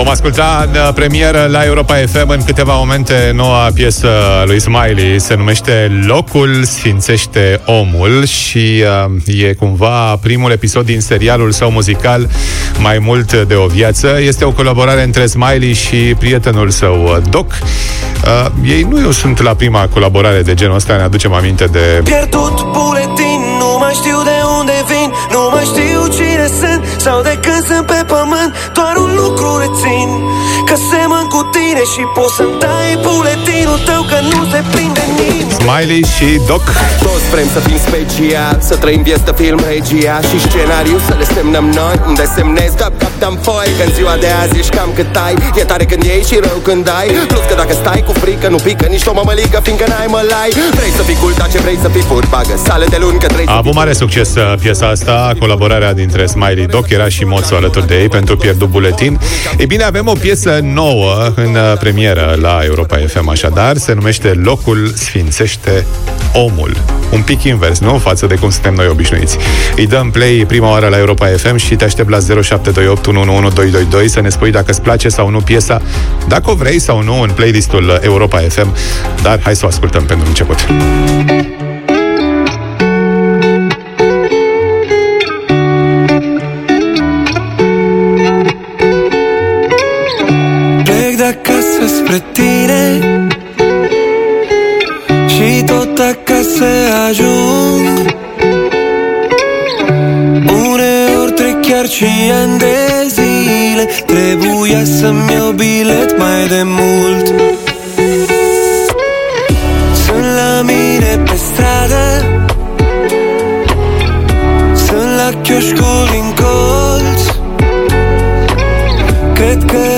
Smiley ne-a povestit mai multe pe Drum cu Prioritate despre piesa pe care am făcut-o în seara asta Hit cu Prioritate, iar ascultătorii au decis dacă intră sau nu în playlistul Europa FM: